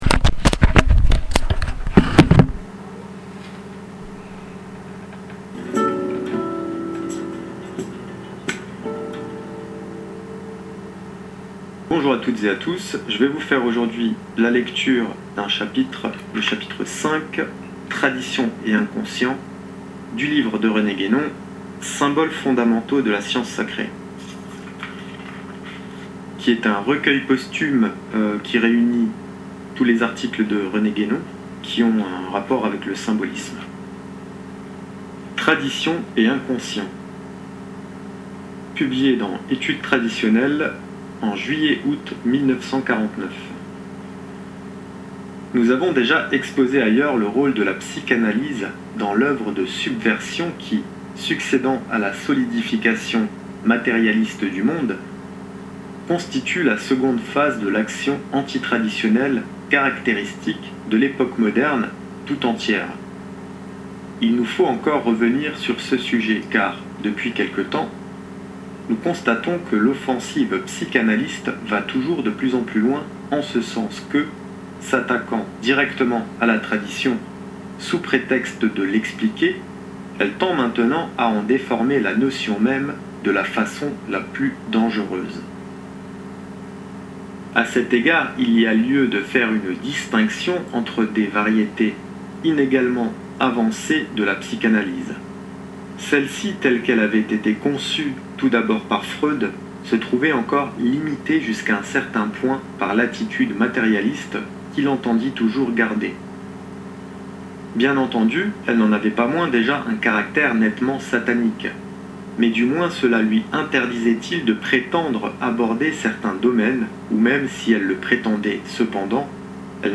Lecture-Tradition-et-inconscient-de-Rene-Guenon-psychanalise-Freud-et-Jung-13m31.mp3